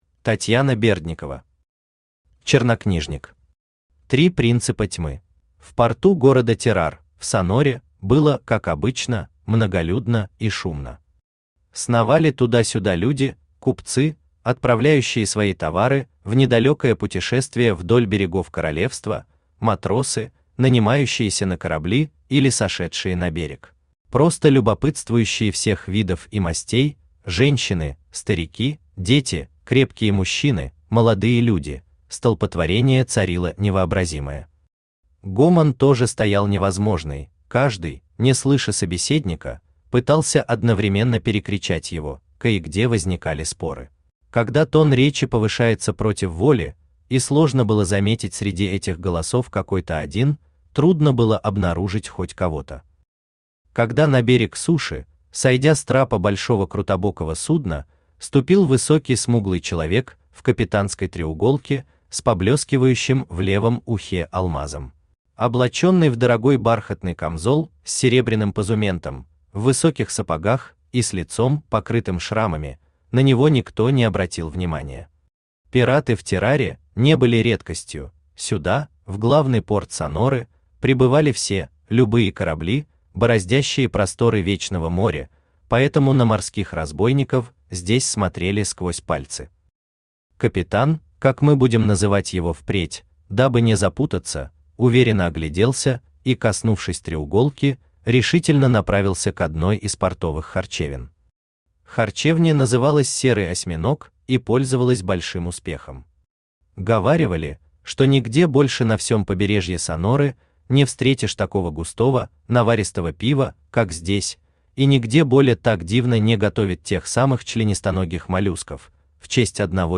Аудиокнига Чернокнижник. Три принципа тьмы | Библиотека аудиокниг
Три принципа тьмы Автор Татьяна Андреевна Бердникова Читает аудиокнигу Авточтец ЛитРес.